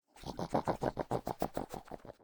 Minecraft Version Minecraft Version latest Latest Release | Latest Snapshot latest / assets / minecraft / sounds / mob / wolf / puglin / panting.ogg Compare With Compare With Latest Release | Latest Snapshot
panting.ogg